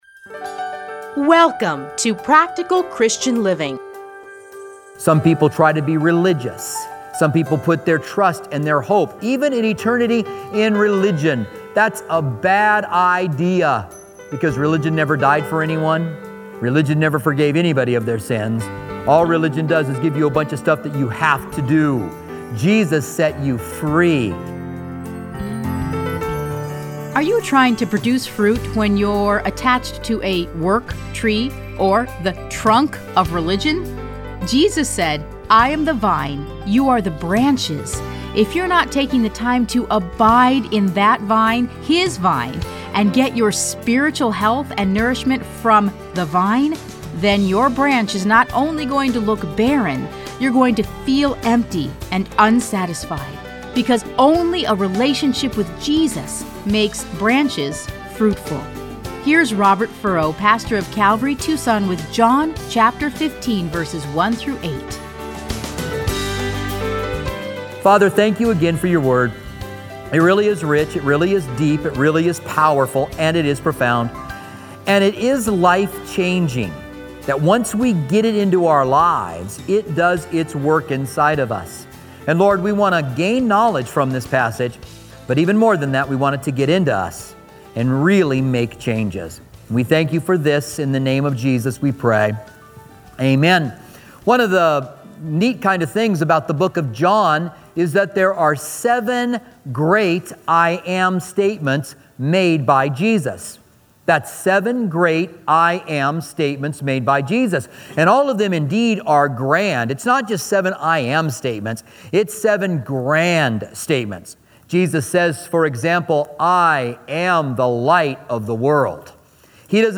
Listen to a teaching from John John 15:1-8.